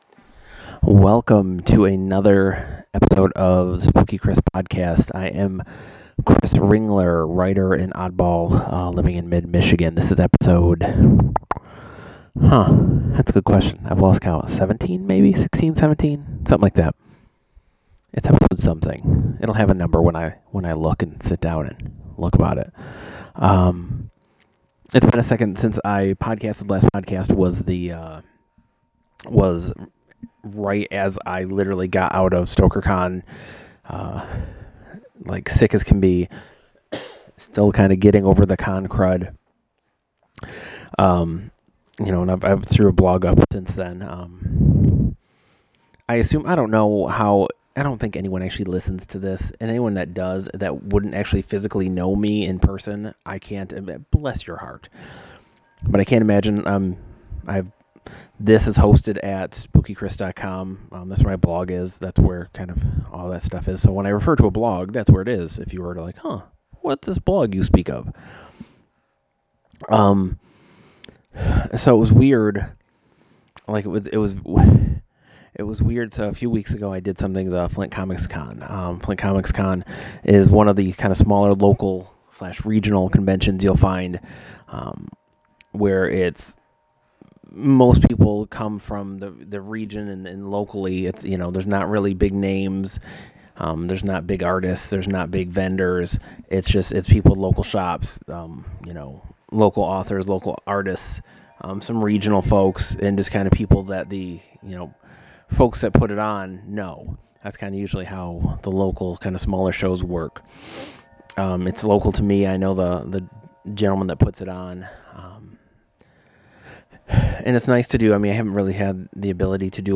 (Pardon the audio, I recorded on my phone so it’s a little woogity).